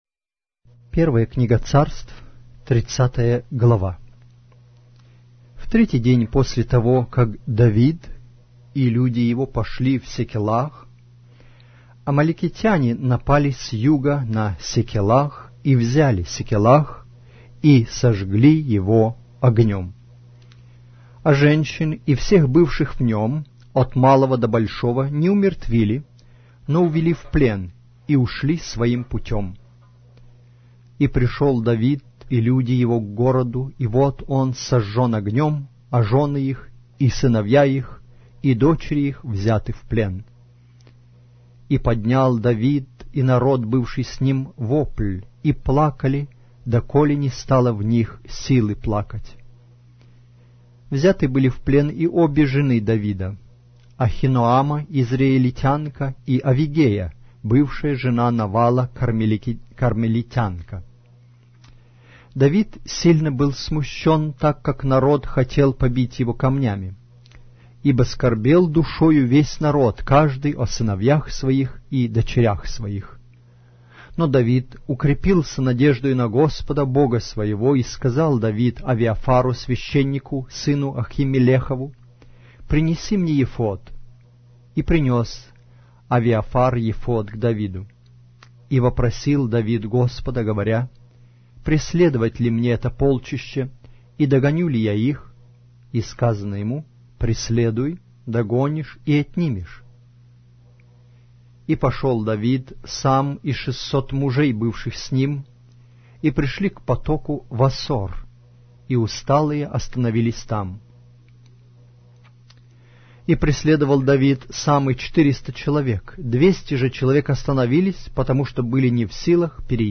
Глава русской Библии с аудио повествования - 1 Samuel, chapter 30 of the Holy Bible in Russian language